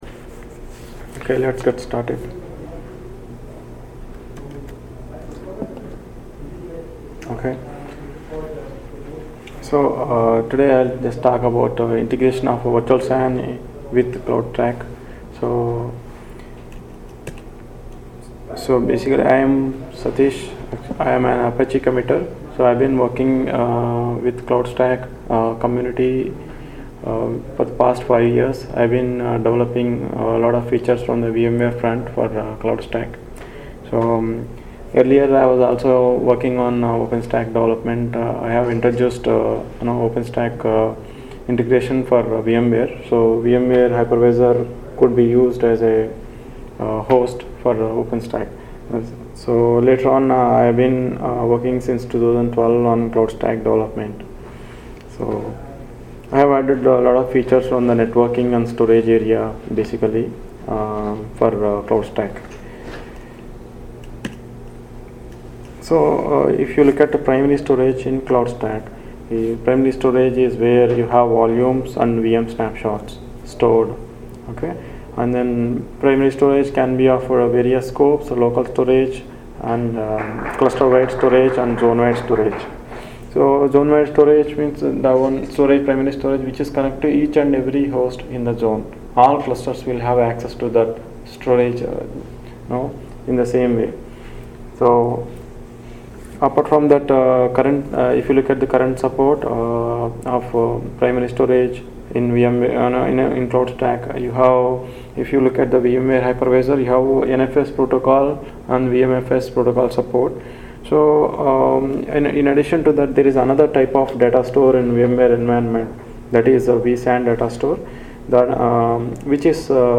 ApacheCon Miami 2017
Citrix Systems R&D Cloudstack Collaboration Conference